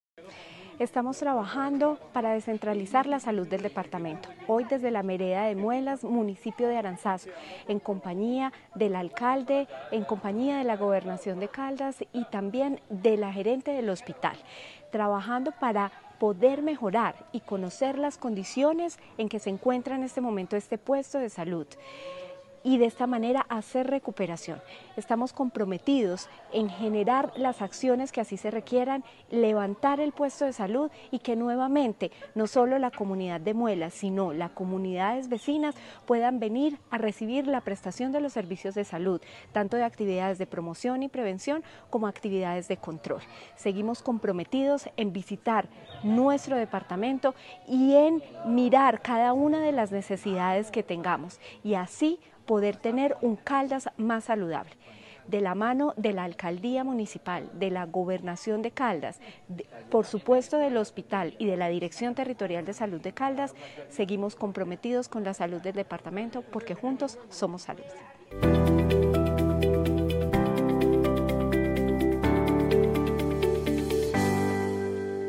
Natalia Castaño Díaz, directora de la DTSC